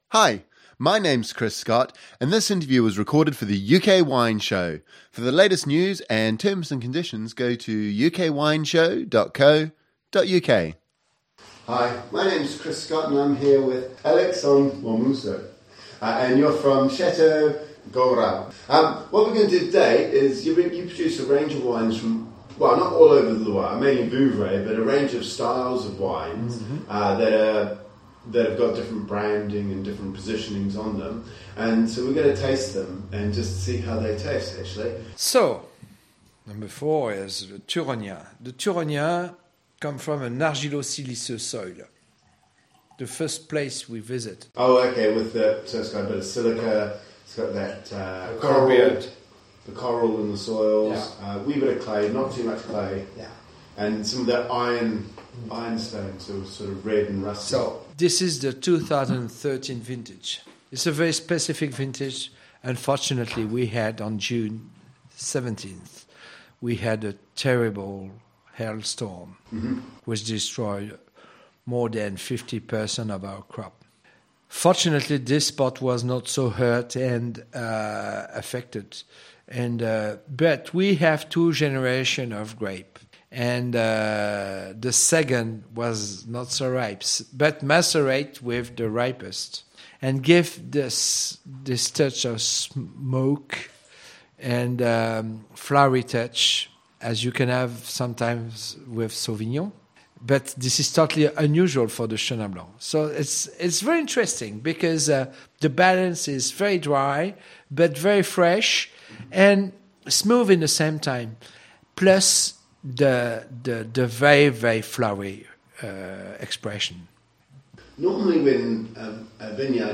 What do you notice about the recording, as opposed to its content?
We also discuss winemaking techniques and barrel fermentation.